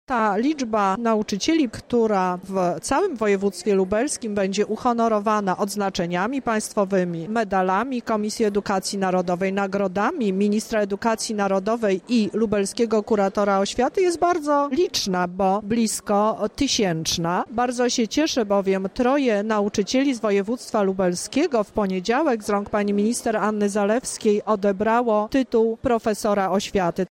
Na wojewódzkich obchodach Dnia Edukacji Narodowej w Lubelskim Parku Naukowo Technologicznym zostały wręczone odznaczenia państwowe dla ponad 200 pedagogów.
O wszystkich wyróżnionych z okazji święta nauczycieli, mówi Teresa Misiuk, Lubelski Kurator Oświaty